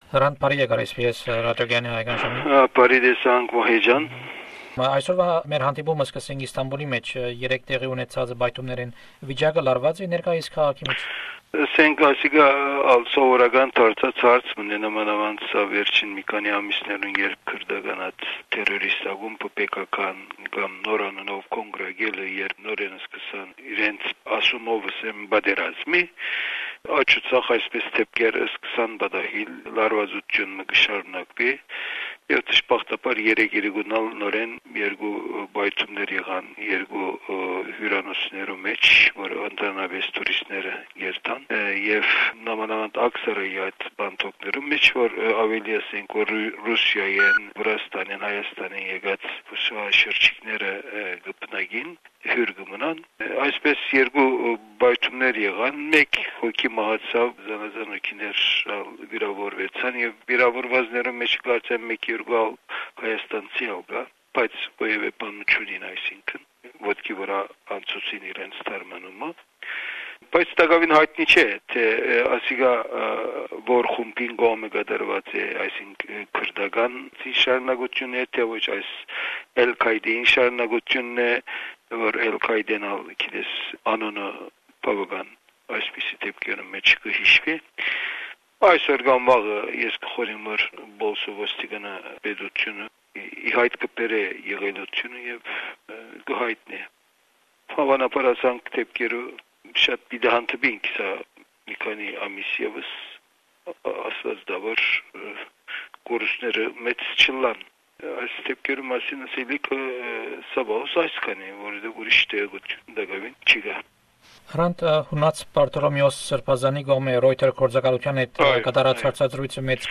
In memory of the 10th anniversary of the assassination of Hrant Dink, we bring you the interviews he gave to SBS Radios Armenian program. This interview was recorded in August 2004.